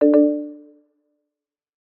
chime-frontend-MarAI47I.mp3